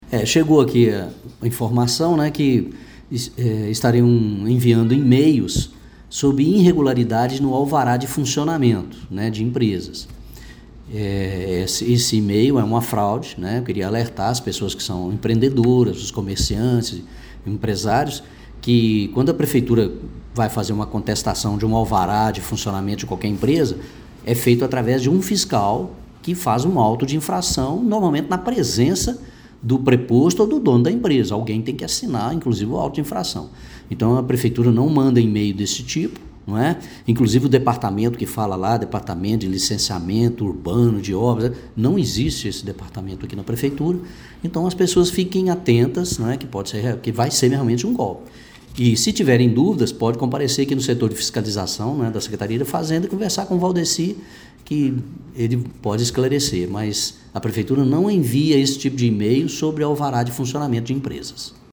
Além disso, a Secretaria Municipal de Gestão Fazendária não envia e-mail para ninguém para tratar de renovação de alvará de funcionamento, como explica o titular da pasta, José Leonardo Martins Pinto: